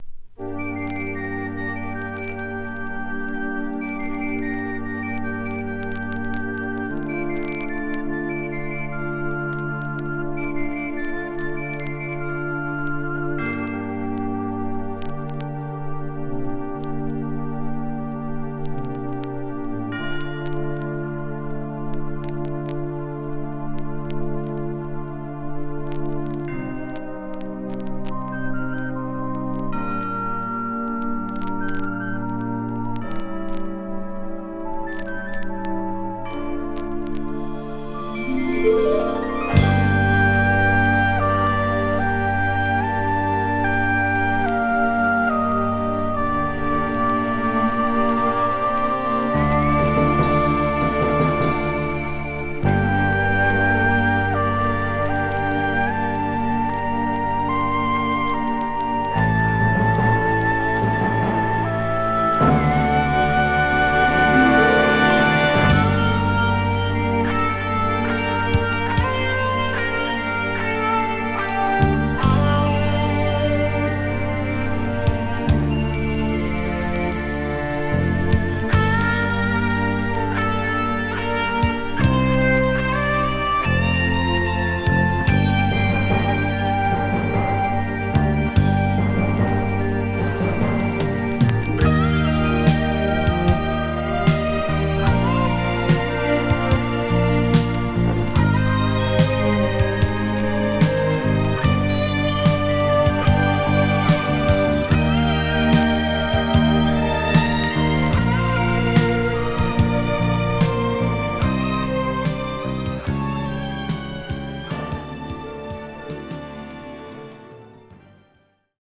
スリリングな
ファンキー・ディスコ